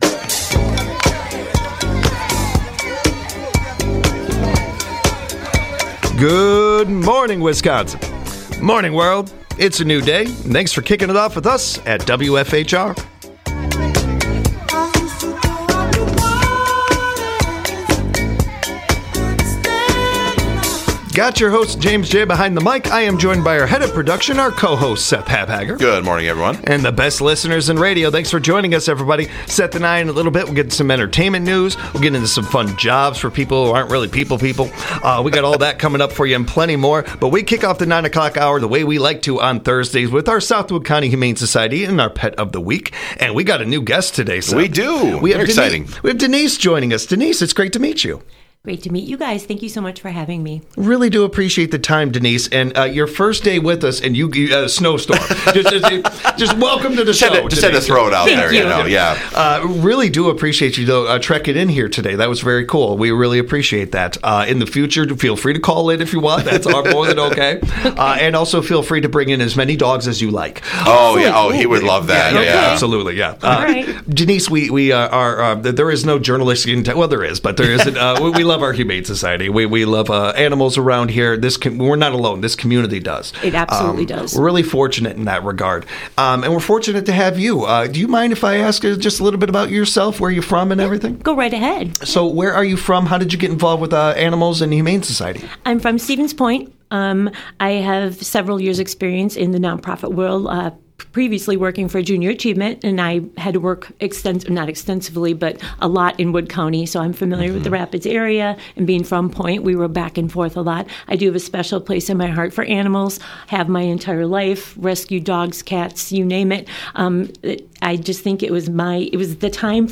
Every Thursday the team kicks the second hour off with a visit from the South Wood County Humane Society, and their Pet of the Week.